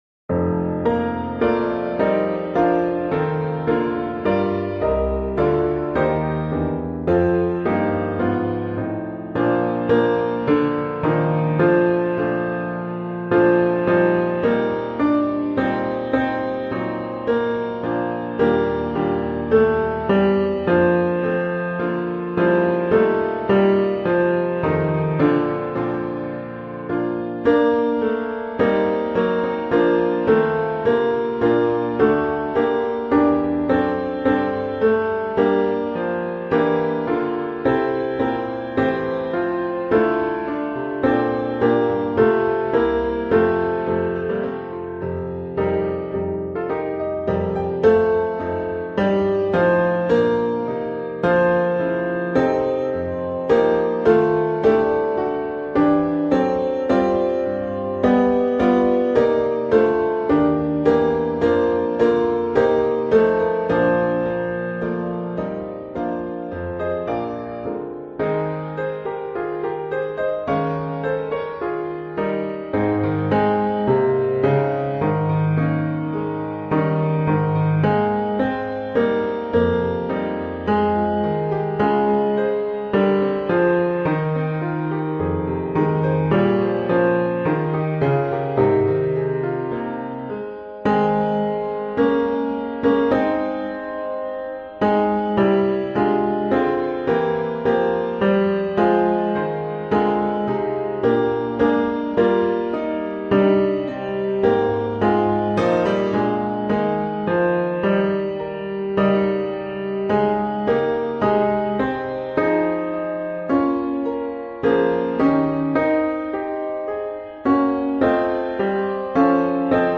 O Zion, Haste – Tenor